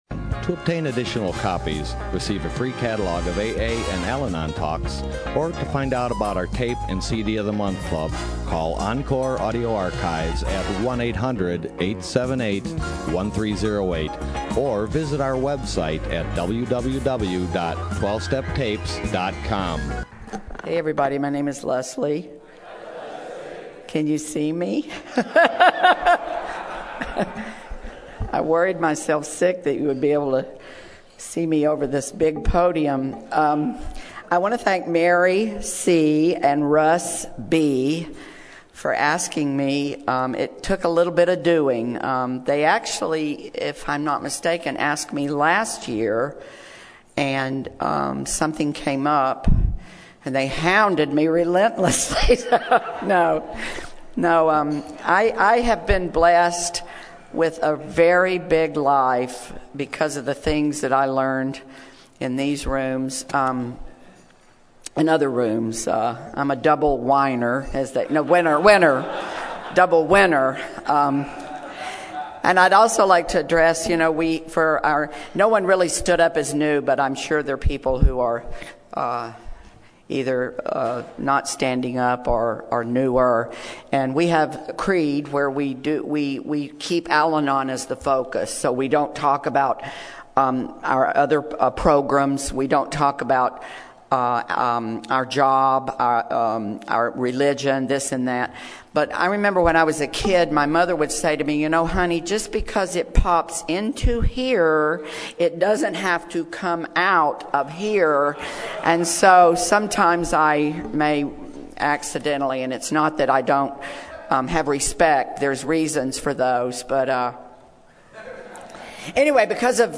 SUNDAY KEYNOTE &#8211